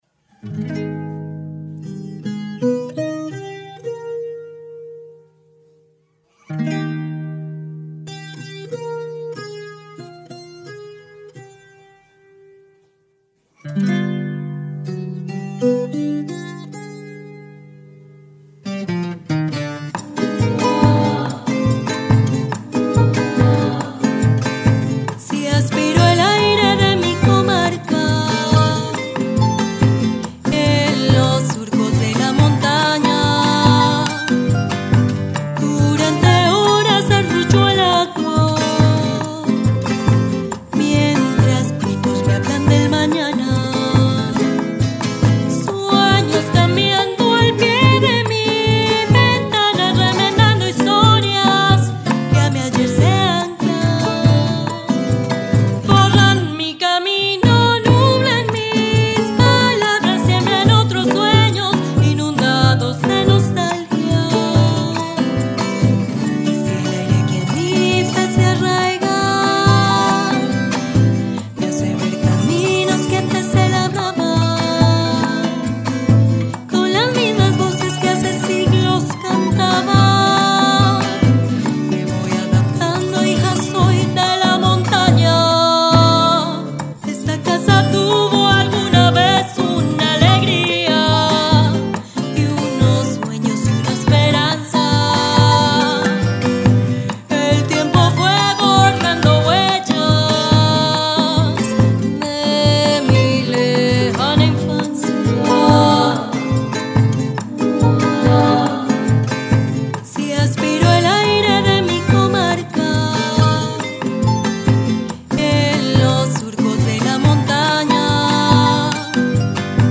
Canción
voz y percusión.
bandola, tiple y guitarra.